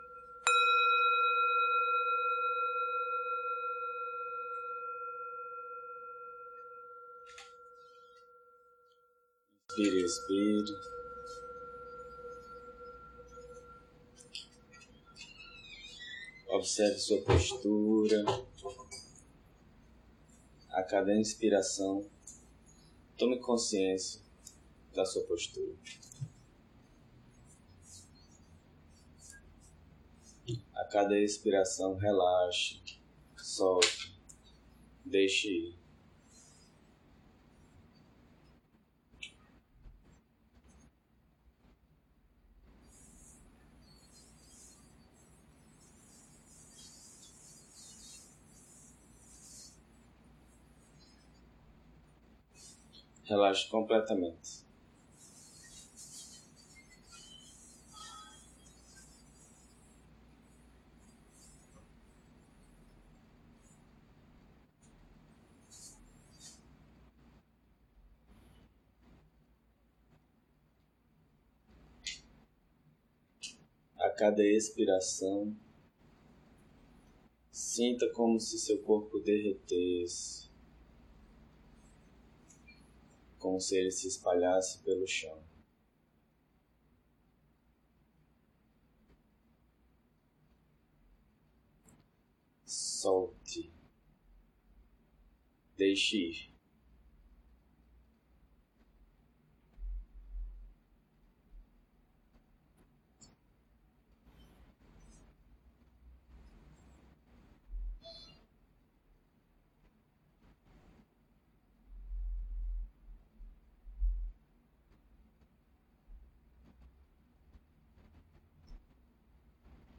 Encontro ocorrido no CEBB Recife, sala encruzilhada em 14 de fevereiro de 2019. Ciclo de estudo: A operação da mente na visão budista através dos 12 elos.